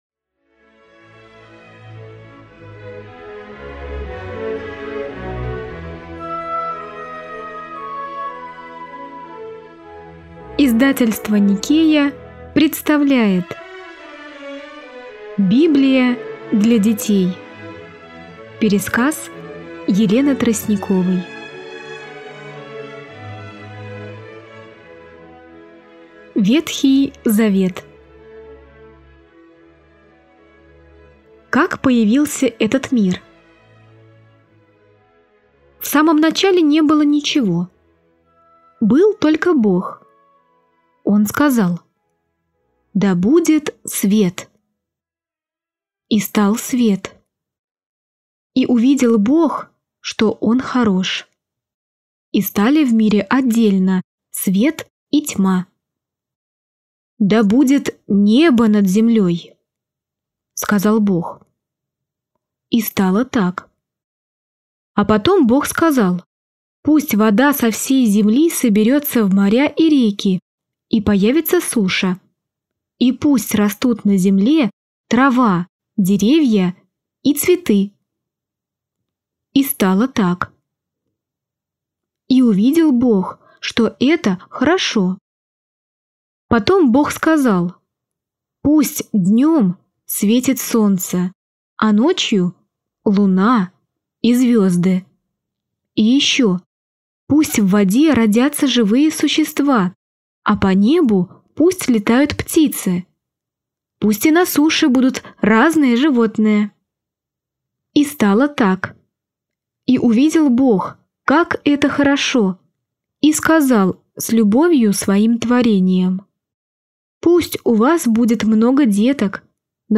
Аудиокнига Библия для детей | Библиотека аудиокниг